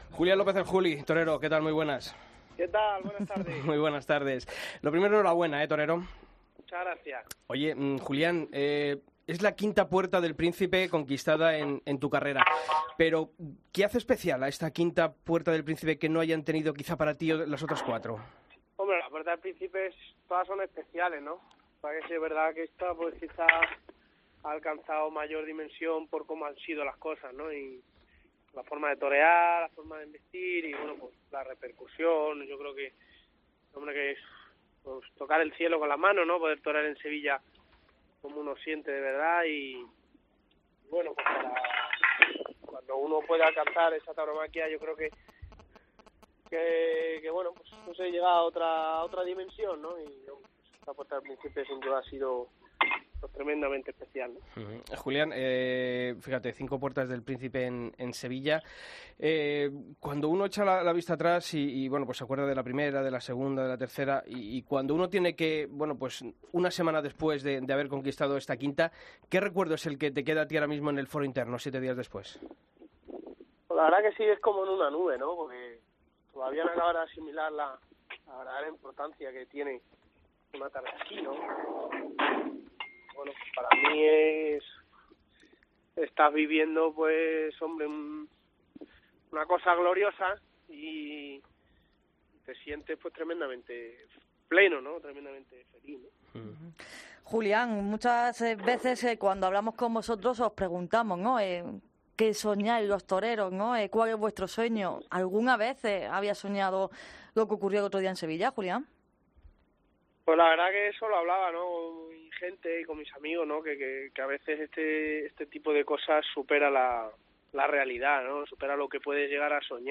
Escucha la entrevista a El Juli en El Albero